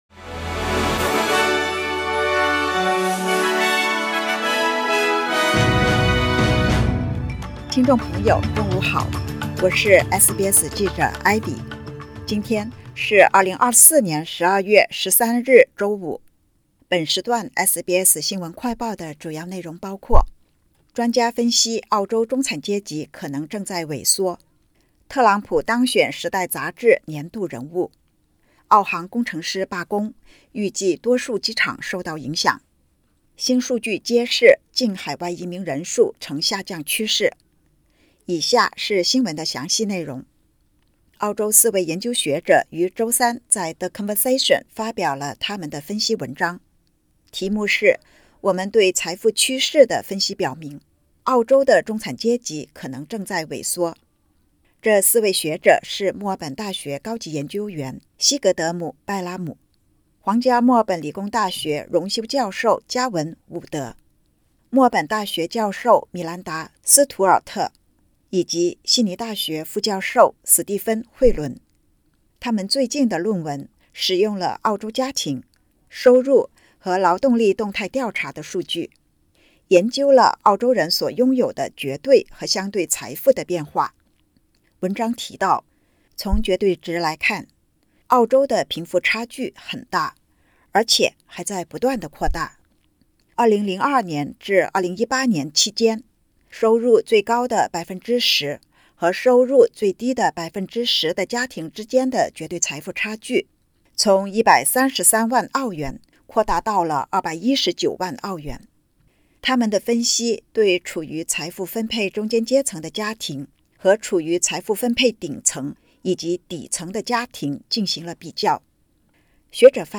【SBS新闻快报】财富趋势解读：澳洲中产阶层正在萎缩？